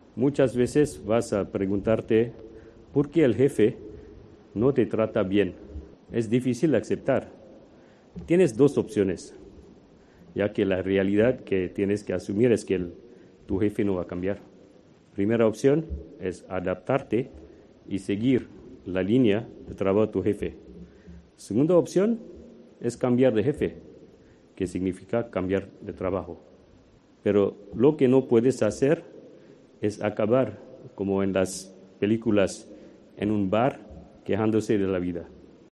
El presidente del Valencia CF se dirige a los alumnos de un Master y acaba hablando de la violencia en el fútbol
Sobre el césped de Mestalla.